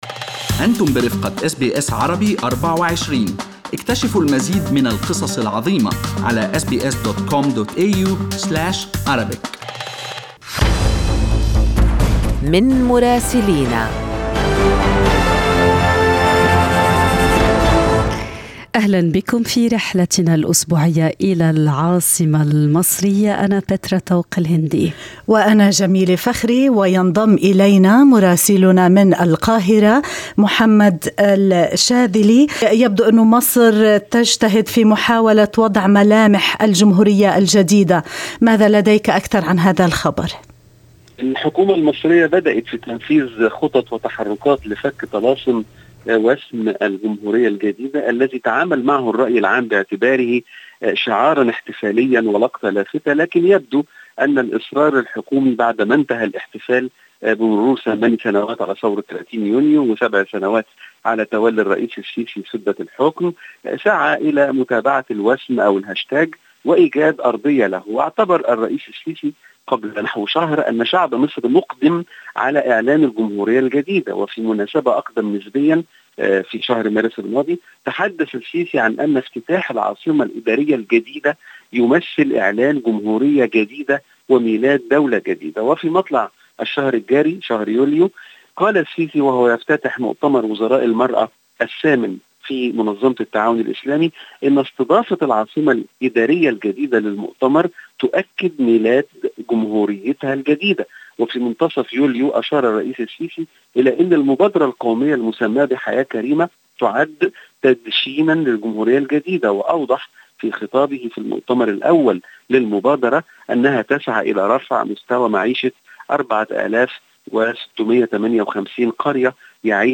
من مراسلينا: أخبار مصر في أسبوع 28/7/2021